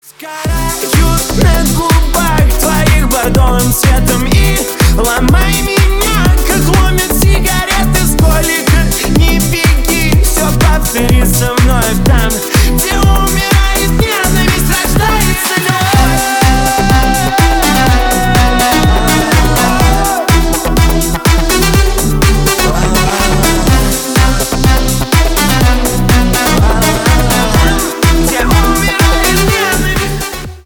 поп
ремиксы